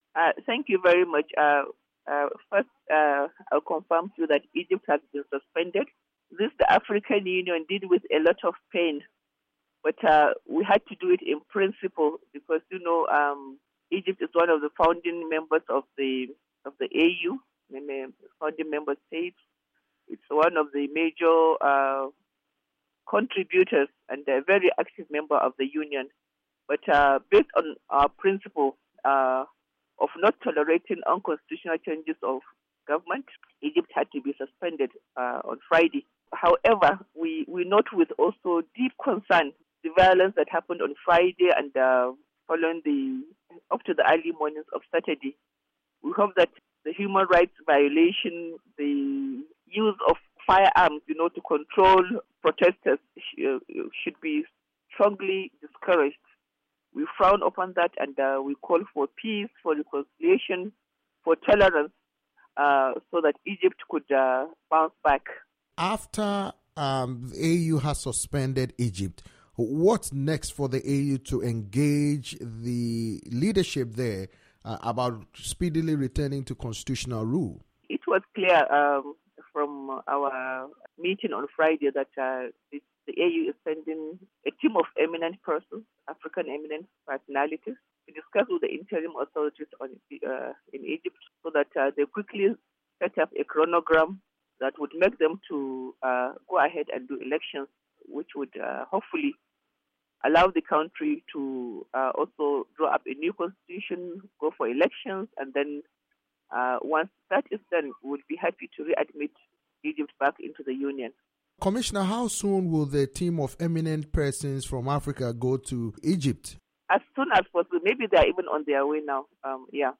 interview with Aisha Abdullahi, AU Political Affairs Commissioner